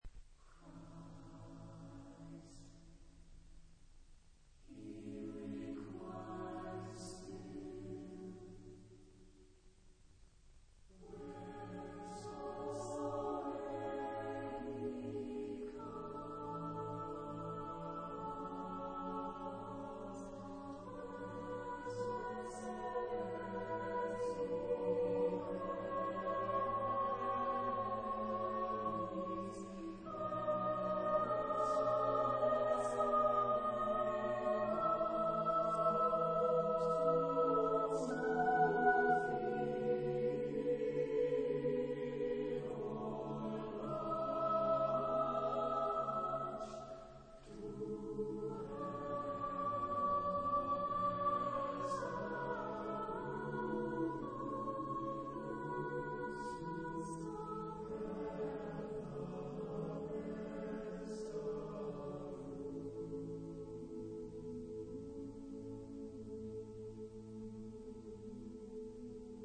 Epoque : 20ème s.
Genre-Style-Forme : Motet ; Sacré ; Hymne (sacré)
Type de choeur : SATB  (4 voix mixtes )
Tonalité : la bémol majeur